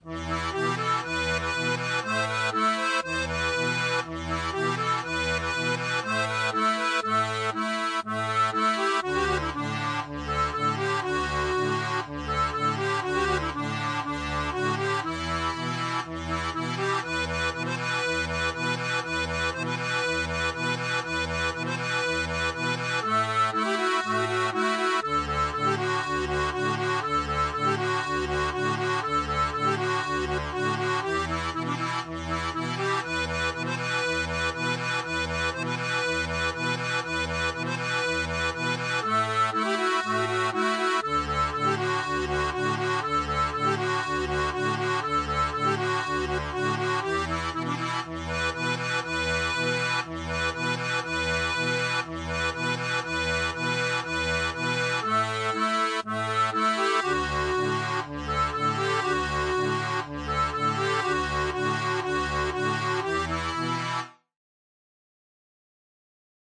• une tablature pour diato 2 rangs
Chanson française